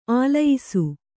♪ ālayisu